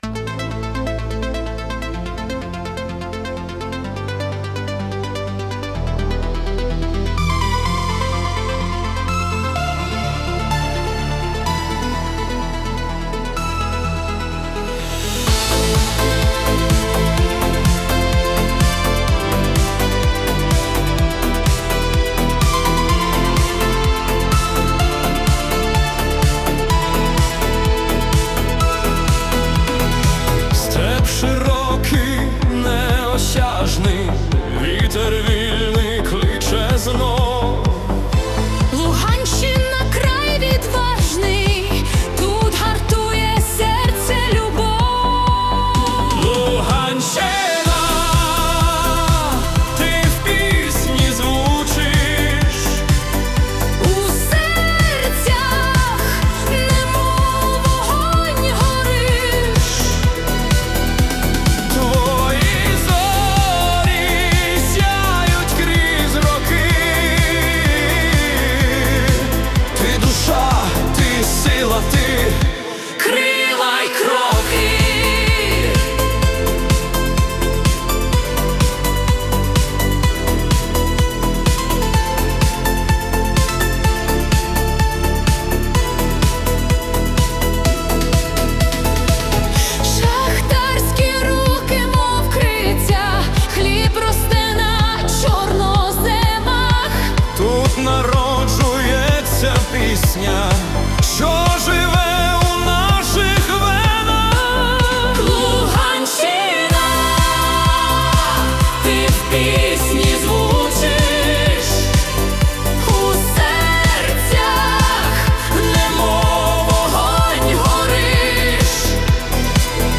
🎵 Жанр: Patriotic Anthem